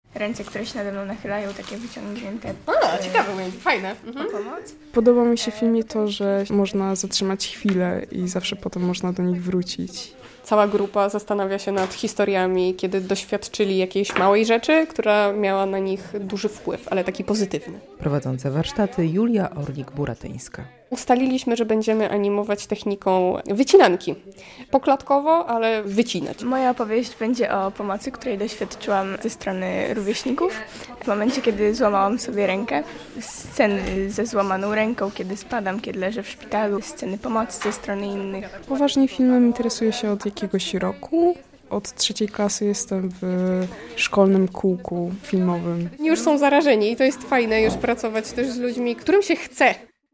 Warsztaty dla młodych filmowców na festiwalu Żubroffka - relacja